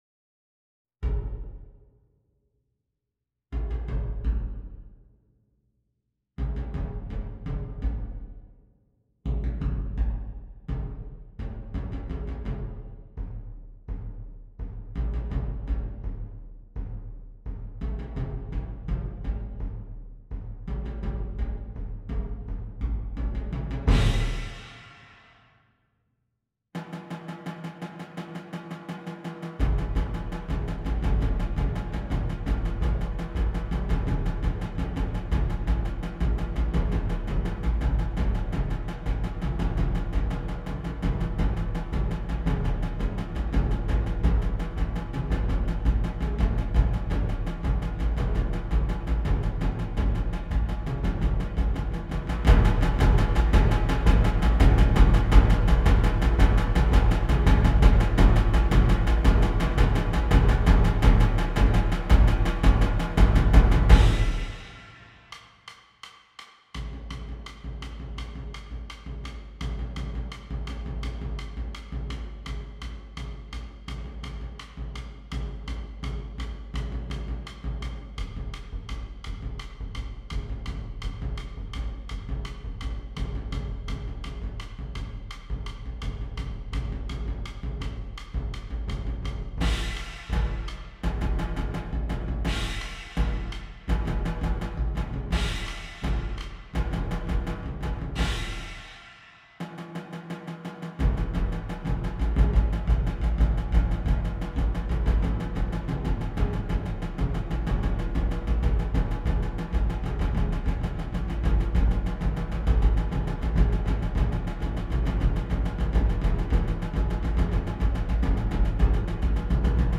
Percussion Ensemble
Inspired by the Japanese style of drumming